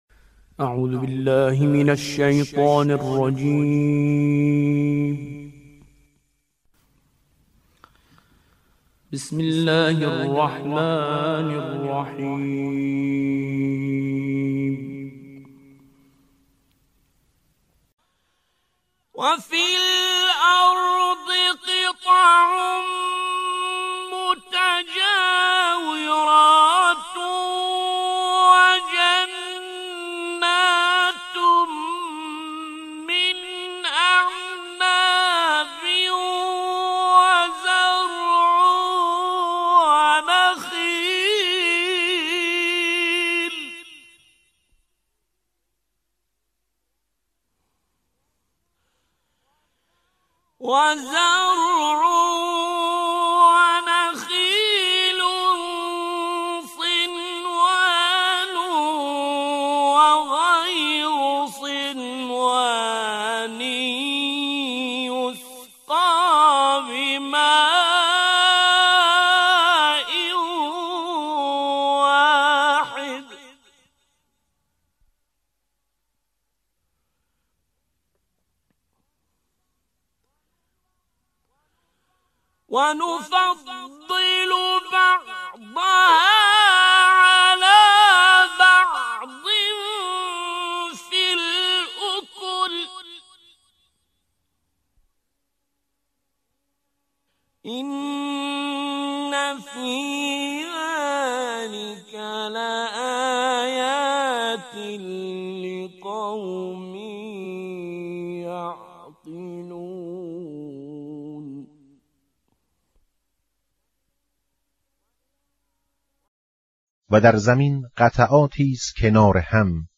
قرائت ،ترجمه و تفسیر صوتی آیه شروع فصل دوم کتاب انسان و محیط زیست